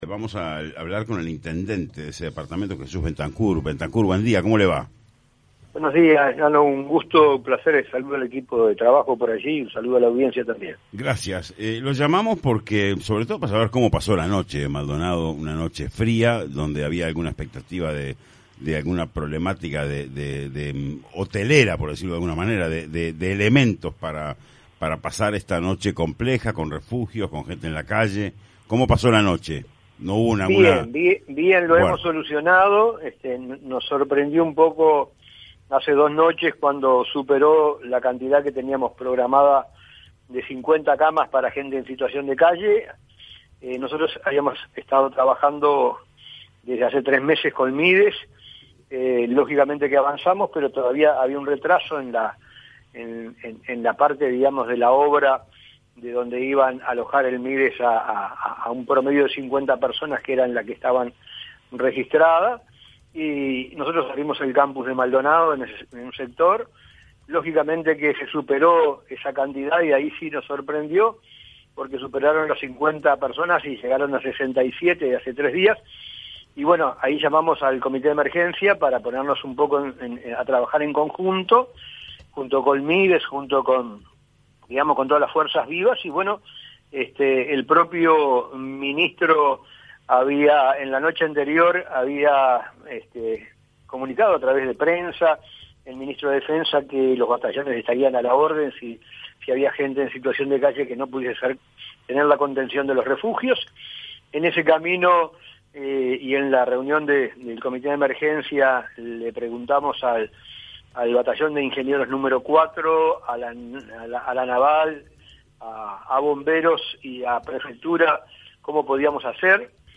Esta mañana Punto de Encuentro recibió al Intendente de Maldonado, Jesús Bentancur, para abordar la problemática de personas en situación de calle en el departamento y la falta de cupos en refugios.